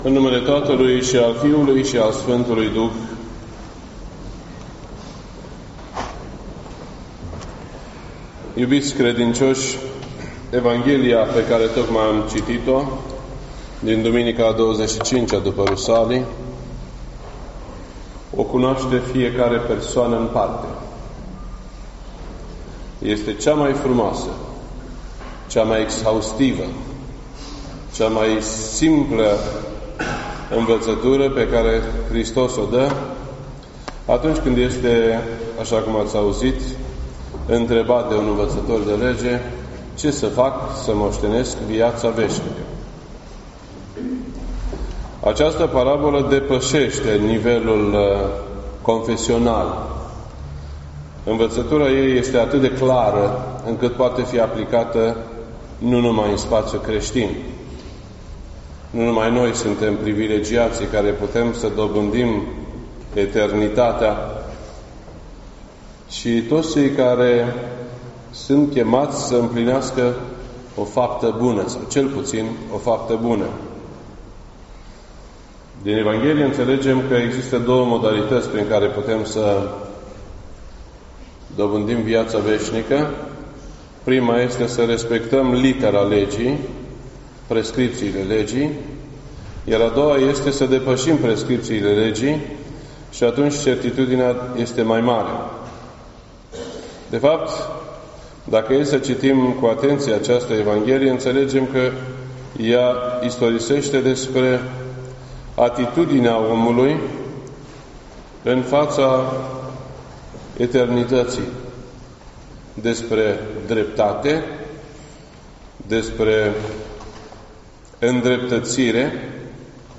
This entry was posted on Sunday, November 12th, 2017 at 1:54 PM and is filed under Predici ortodoxe in format audio.